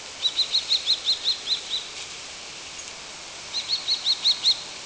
FALCO TINNUNCULUS - KESTREL - GHEPPIO
E 11°12' - ALTITUDE: 0 m. - VOCALIZATION TYPE: advertising/contact call when perched. - SEX/AGE: unknown - COMMENT: the bird is quite far from the mic, therefore the signal to noise ratio is very low and the harmonics are only barely visibles in the second spectrogram. The background noise has been filtered out from the audio file.